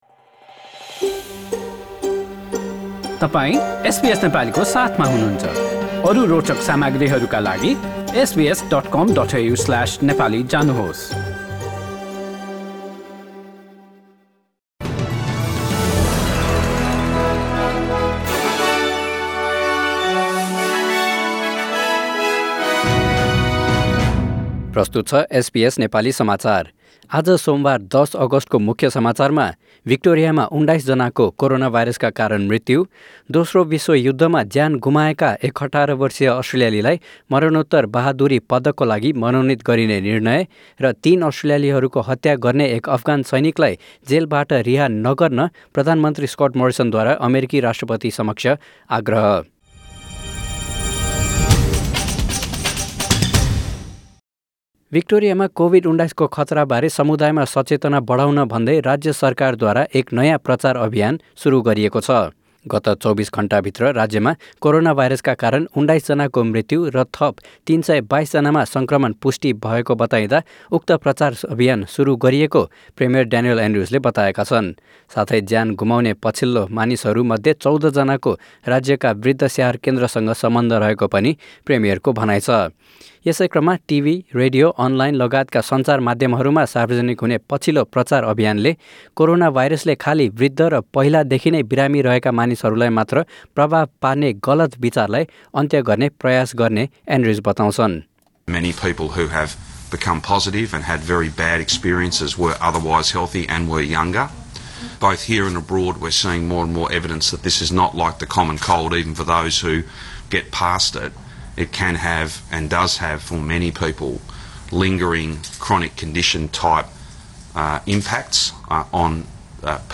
SBS Nepali Australia News: Monday 10 August 2020
Listen to the latest news headlines in Australia from SBS Nepali radio.